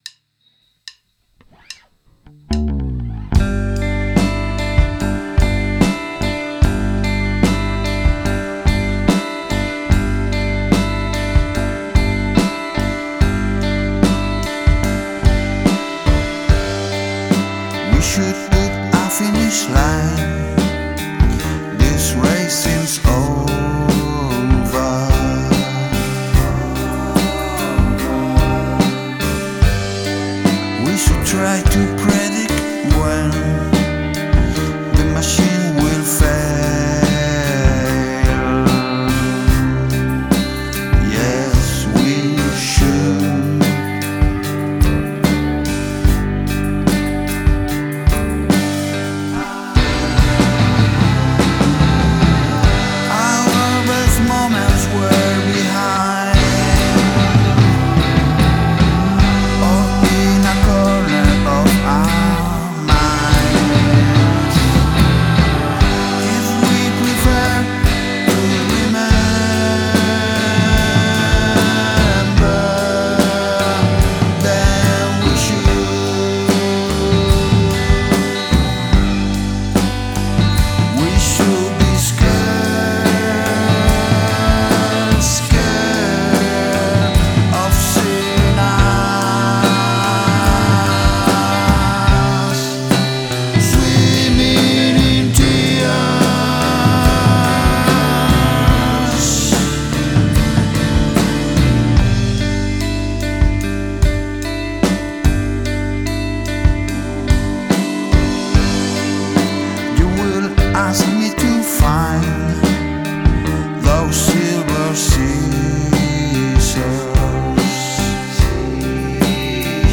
Have a guest play a household item on the track